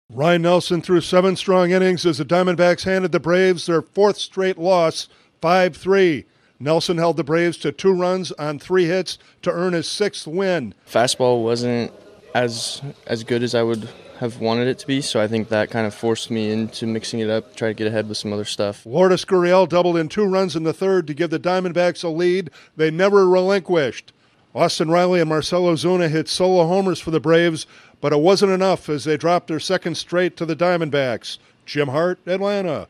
The Diamondbacks knock off the Braves for the second straight day. Correspondent